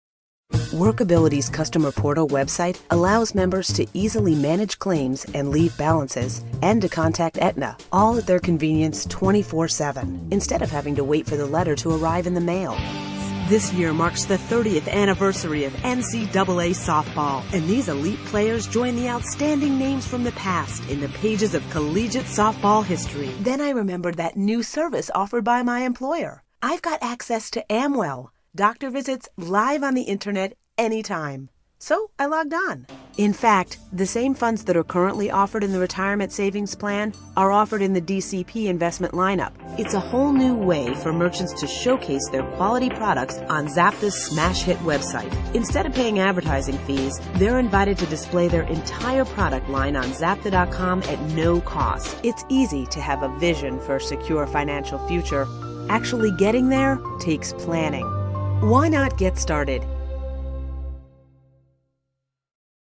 Narration Demo